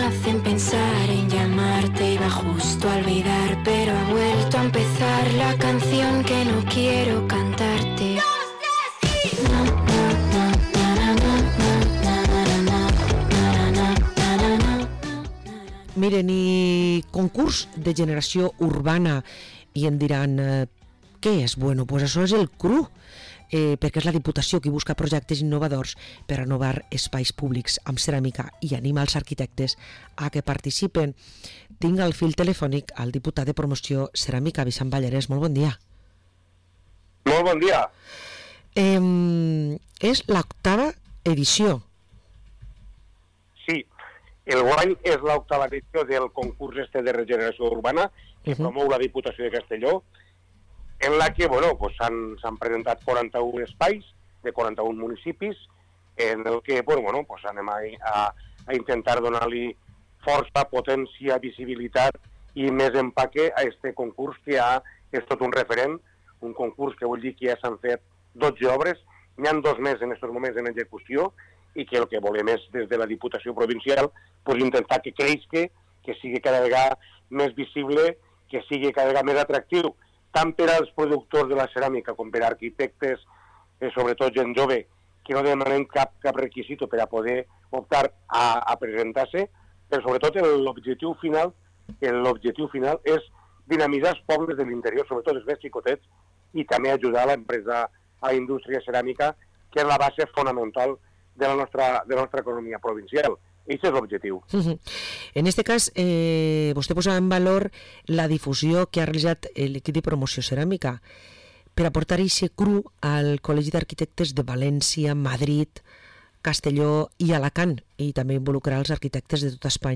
Parlem amb Vicent Pallarés, Diputat de Promoció Ceràmica